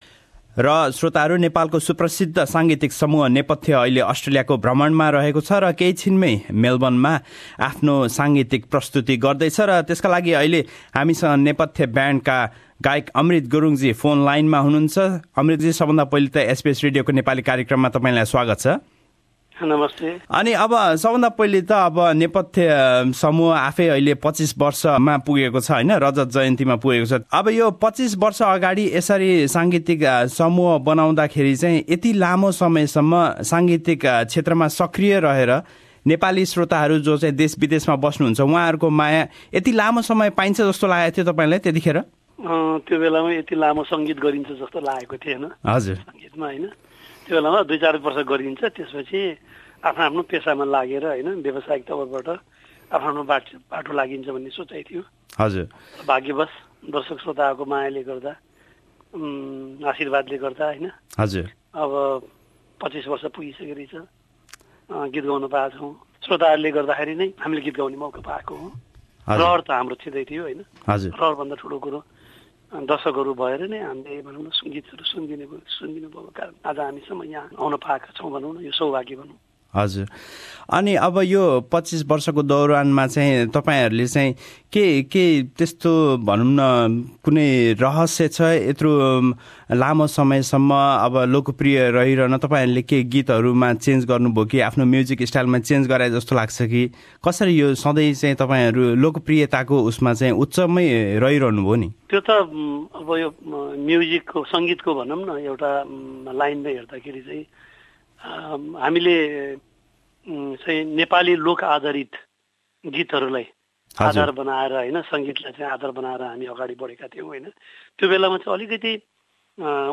25 years old Nepali music band, Nepathya is currently on a tour in Australia. We spoke with Amrit Gurung on how his band has been so successful to continually garner appreciation from Nepali music listeners.